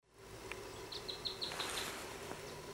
Bandurrita Chaqueña (Tarphonomus certhioides)
Nombre en inglés: Chaco Earthcreeper
Localidad o área protegida: Reserva Provincial Parque Luro
Localización detallada: Sendero del Monte
Condición: Silvestre
Certeza: Fotografiada, Vocalización Grabada